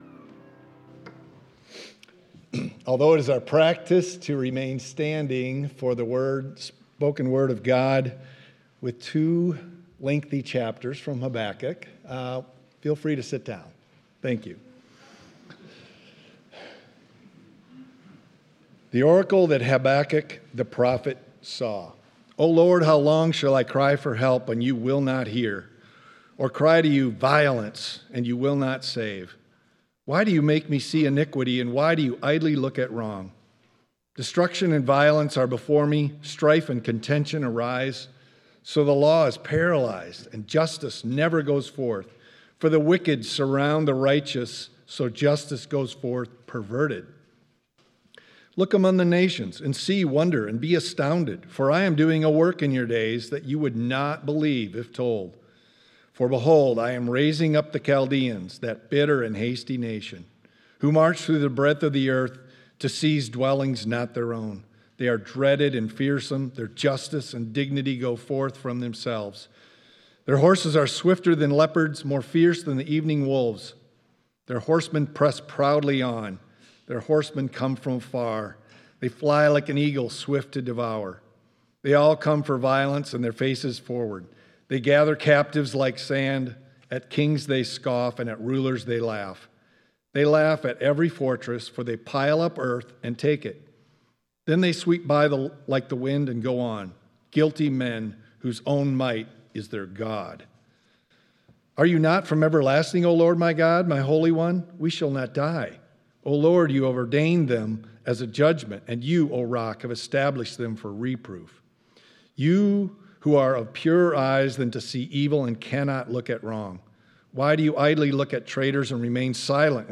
5.18.25 sermon.m4a